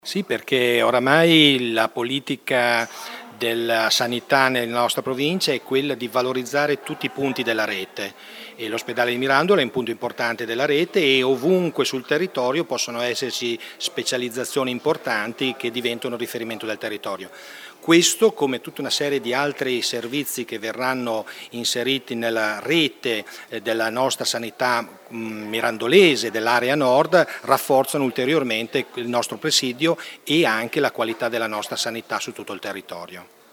Presente alla conferenza stampa di presentazione del “Centro disturbi del sonno” anche il sindaco di Mirandola, Maino Benatti che si è detto certo dell’importante contributo che questo nuovo centro darà al prestigio del Santa Maria Bianca:
Sindaco-Maino-Benatti.mp3